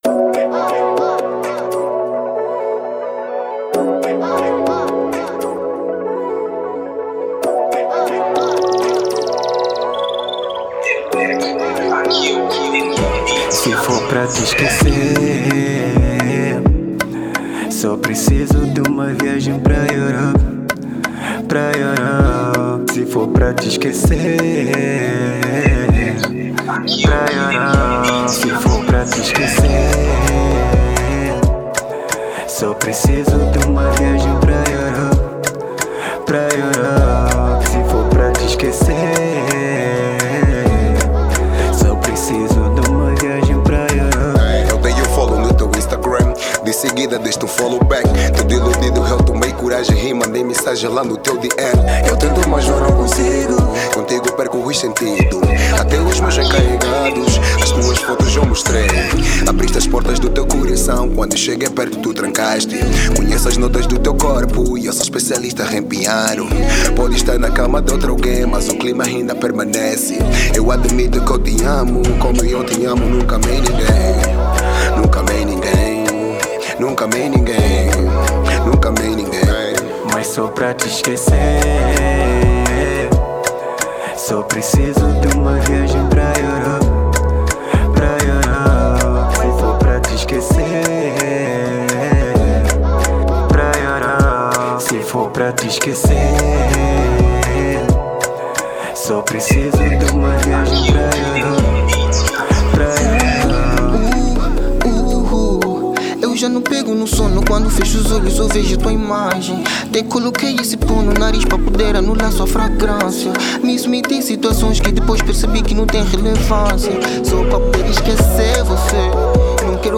Trap Funk